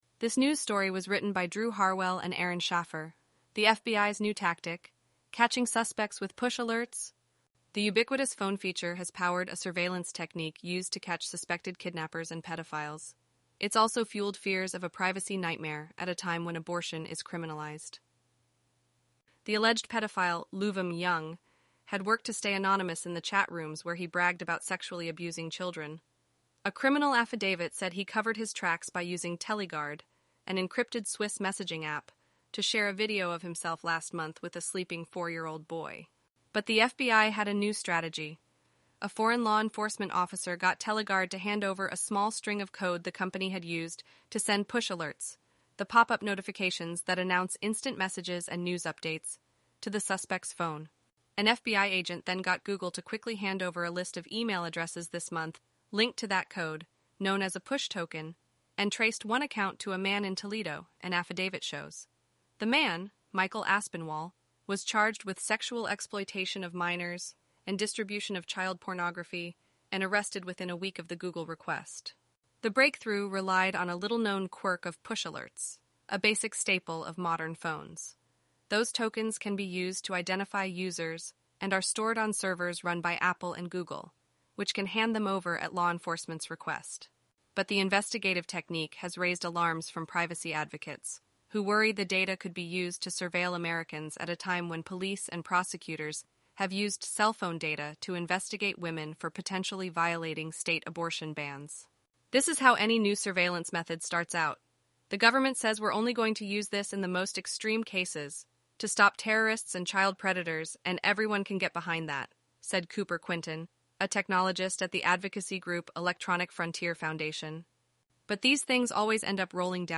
eleven-labs_en-US_Rachel_standard_audio.mp3